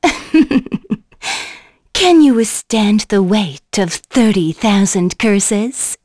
Shamilla-Vox_Skill3.wav